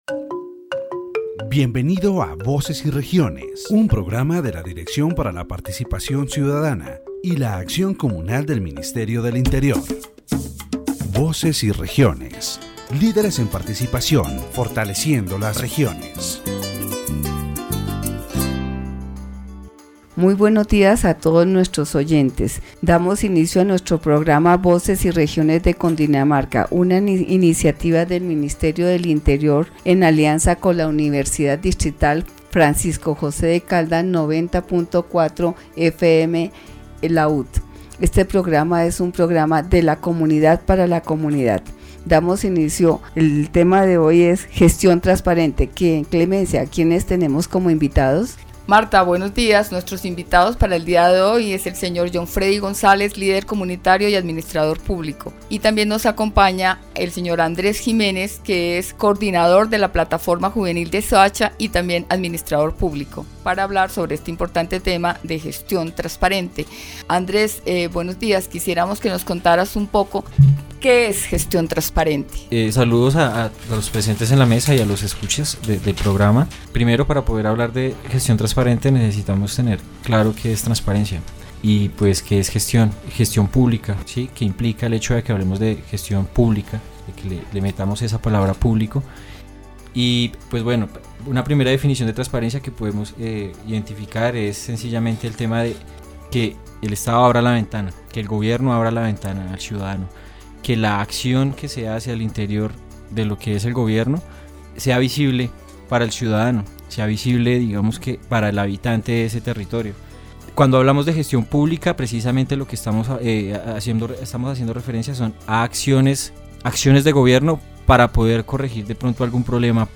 The radio program "Voices and Regions" of the Directorate for Citizen Participation and Communal Action of the Ministry of the Interior focuses on transparent management in the Department of Cundinamarca.